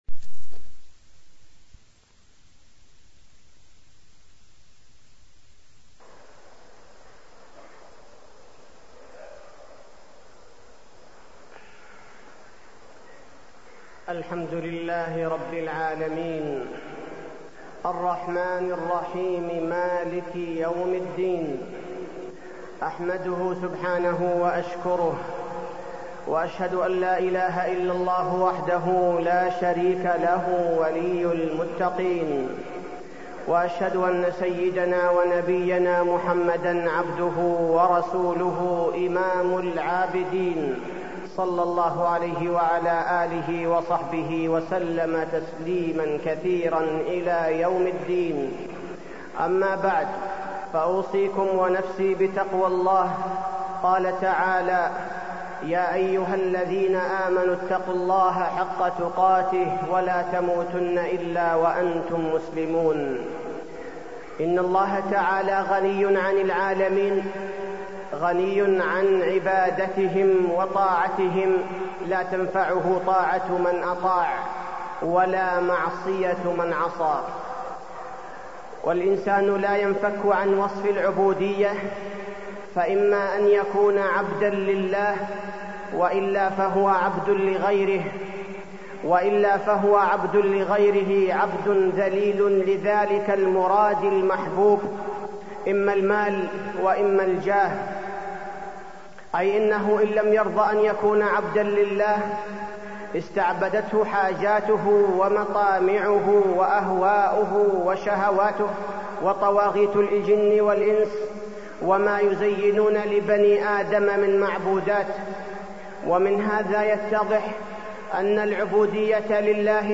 تاريخ النشر ١٣ ذو الحجة ١٤٢٣ هـ المكان: المسجد النبوي الشيخ: فضيلة الشيخ عبدالباري الثبيتي فضيلة الشيخ عبدالباري الثبيتي المواظبة على الأعمال الصالحة